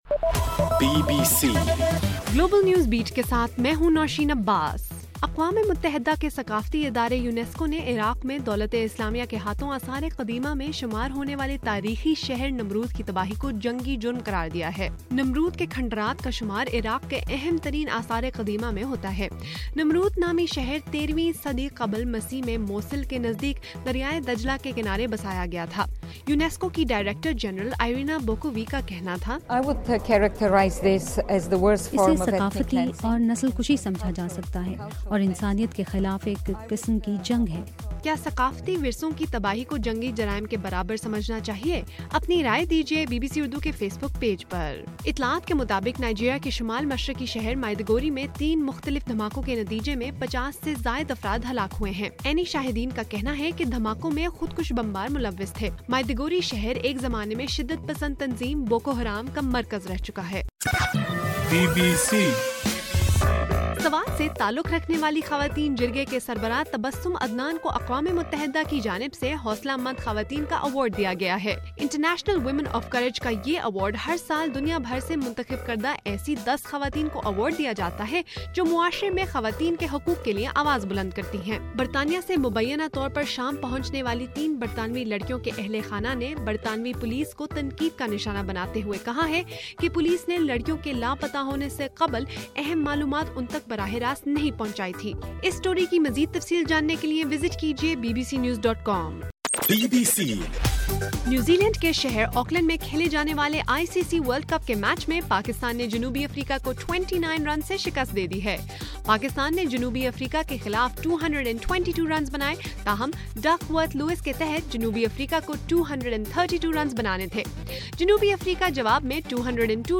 مارچ 7: رات 11 بجے کا گلوبل نیوز بیٹ بُلیٹن